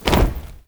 AFROFEET 2-L.wav